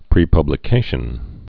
(prē-pŭblĭ-kāshən)